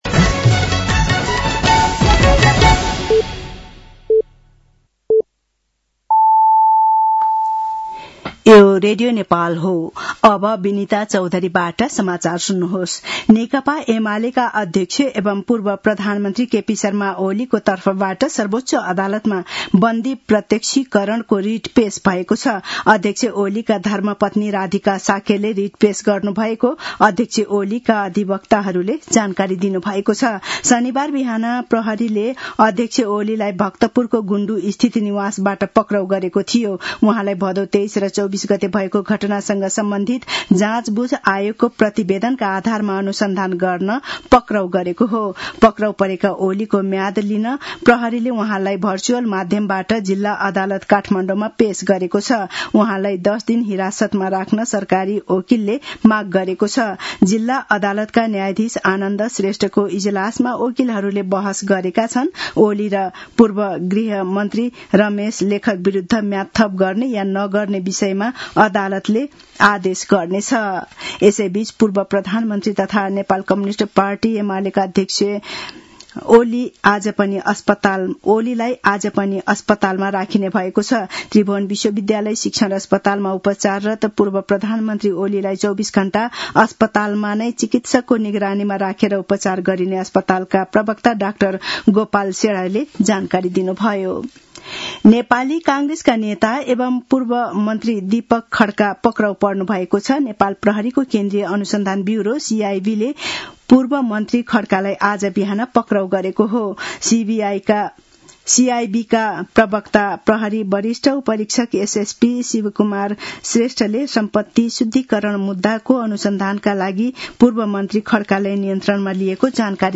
साँझ ५ बजेको नेपाली समाचार : १५ चैत , २०८२